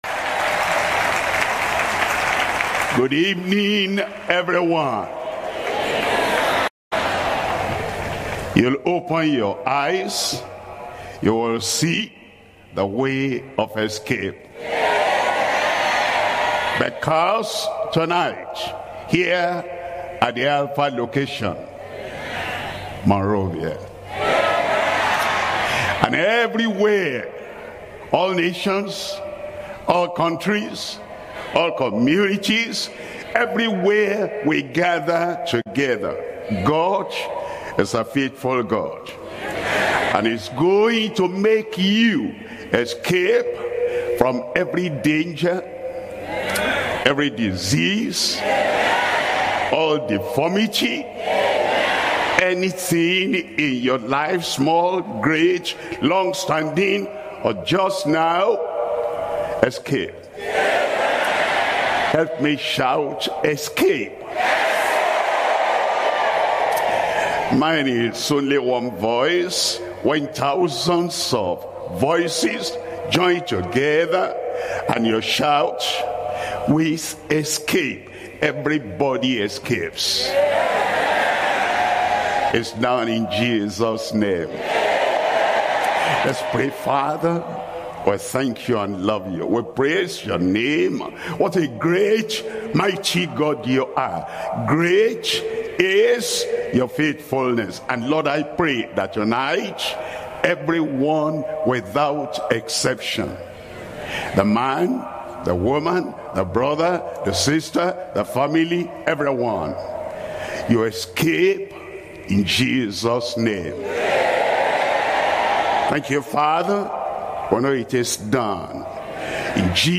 Sermons - Deeper Christian Life Ministry